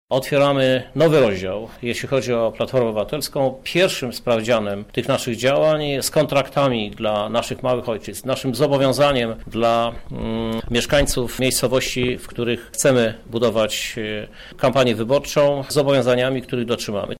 – mówi Krzysztof Żuk , prezydent miasta Lublina